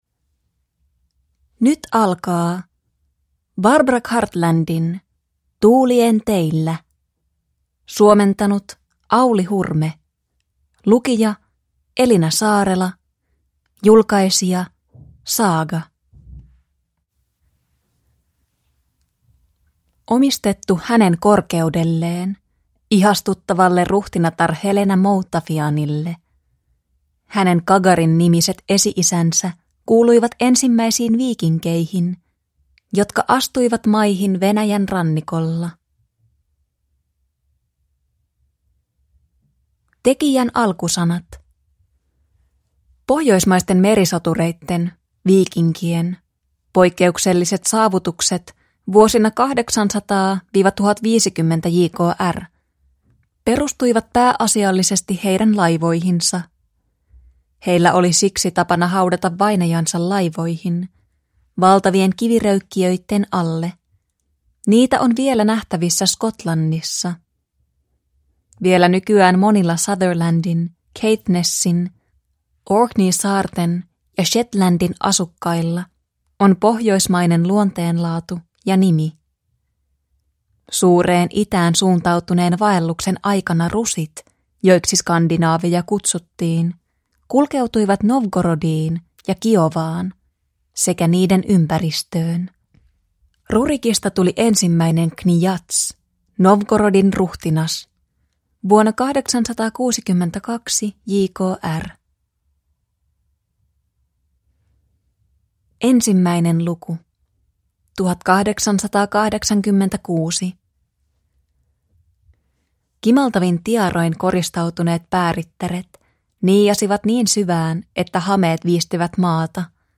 Rakkautta Skotlannissa (ljudbok) av Barbara Cartland